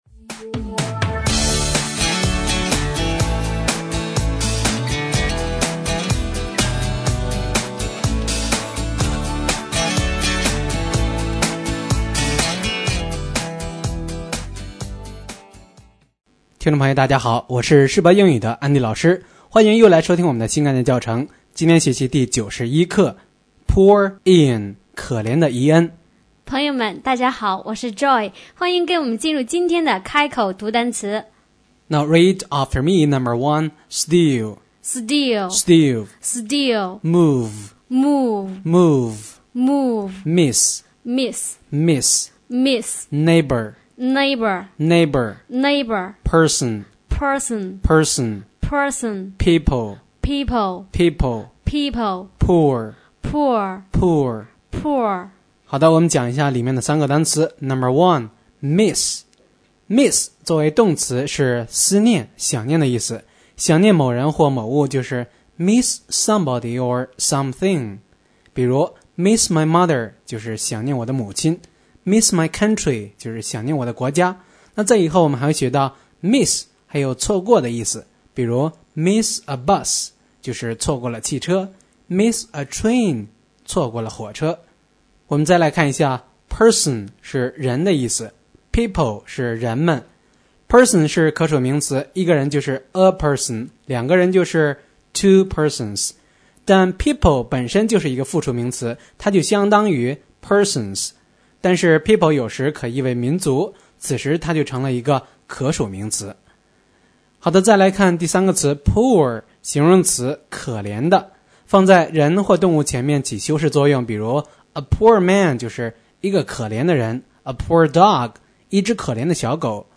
新概念英语第一册第91课【开口读单词】